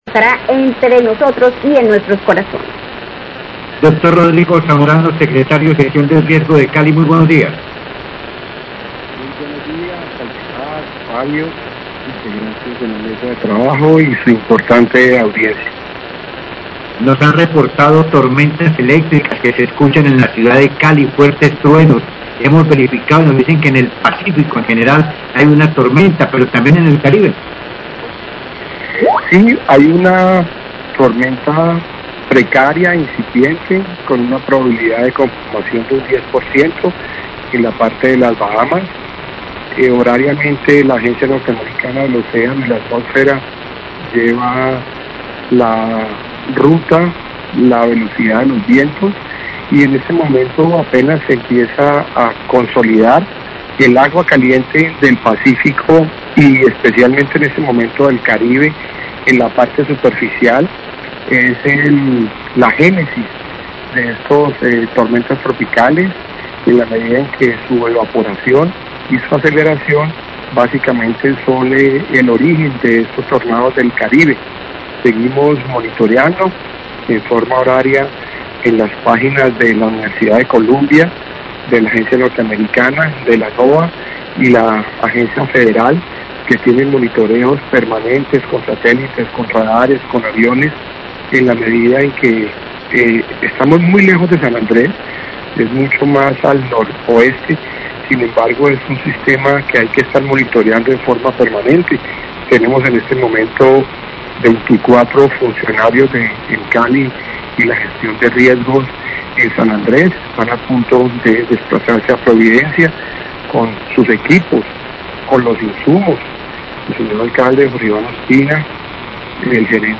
Radio
Secretario de Gestión de Riesgo, Rodrigo Zamorano, habla sobre lo que se espera en materia de clima y da recomendaciones para evitar emergencias por crecidas del río Cauca.  Informó sobre el nivel del embalse de Salvajina.